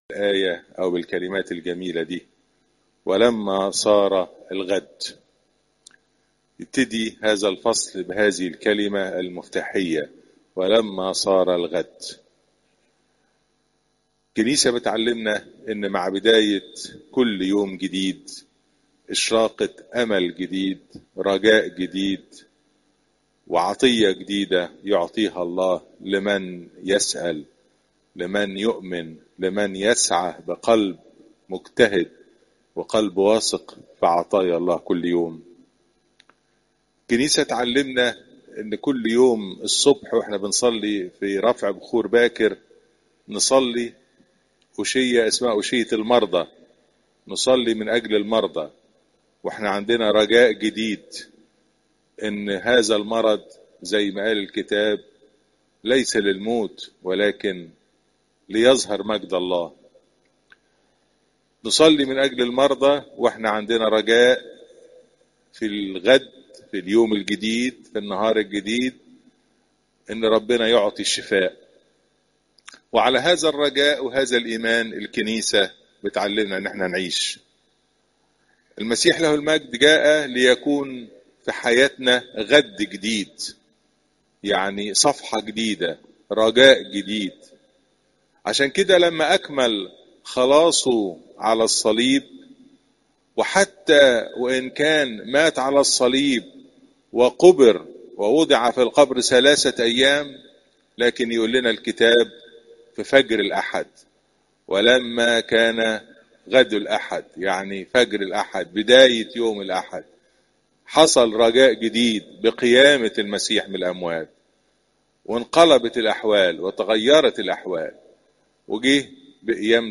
عظات قداسات الكنيسة (لو 7 : 11 - 17)